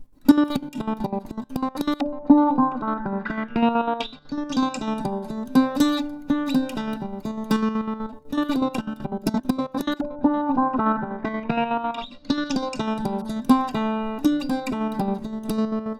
Here is the wet audio.
The plugin I used for the effect was Arturia’s Efx Motions plugin.
I was rather kind to my future self because the idea was a perfect 8-bar melody in 4/4.
Wet-Octave-Motions-Self-Osc-FX-3.wav